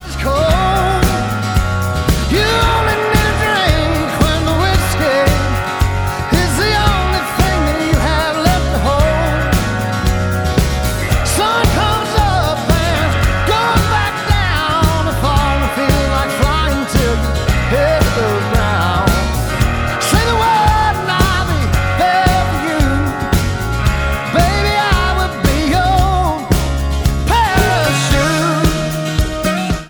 • Country
The song is an uptempo backed by banjo and acoustic guitar